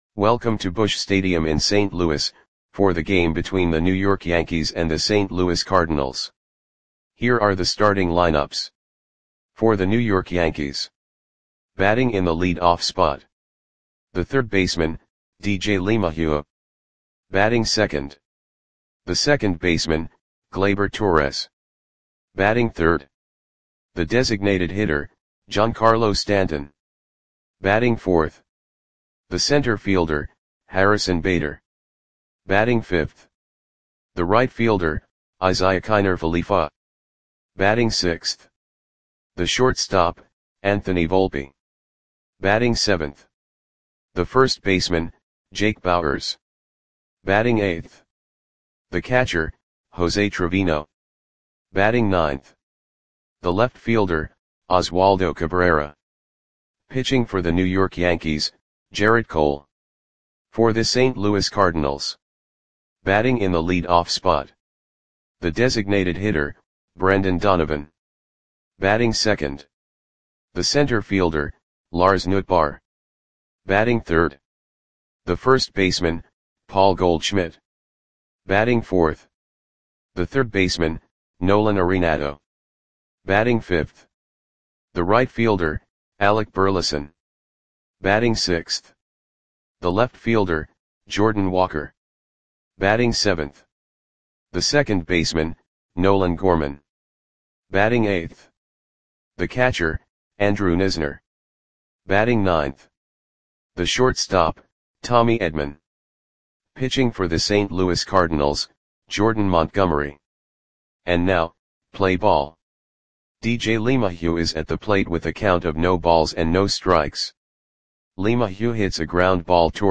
Audio Play-by-Play for St. Louis Cardinals on July 2, 2023
Click the button below to listen to the audio play-by-play.